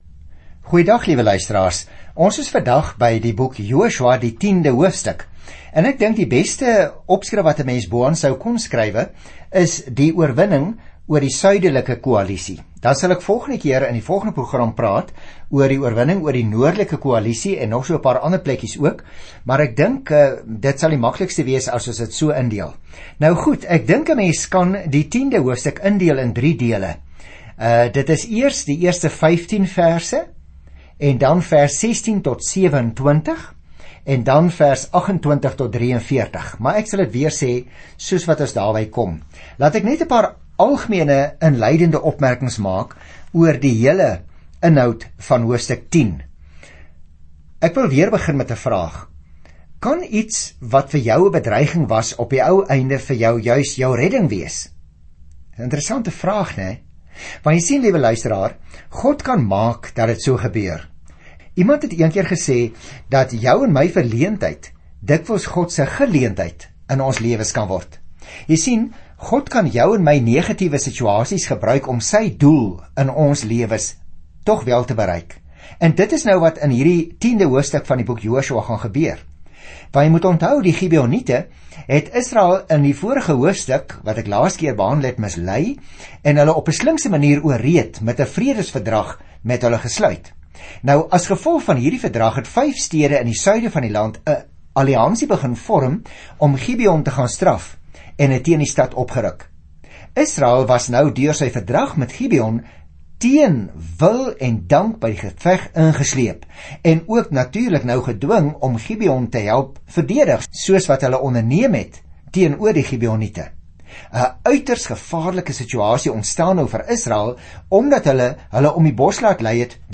Skrif JOSUA 10 Dag 6 Begin met hierdie leesplan Dag 8 Aangaande hierdie leesplan Kom ons noem die boek Josua, "Eksodus: Deel twee," soos 'n nuwe geslag van God se volk die land inneem wat Hy aan hulle belowe het. Reis daagliks deur Joshua terwyl jy na die oudiostudie luister en uitgesoekte verse uit God se woord lees.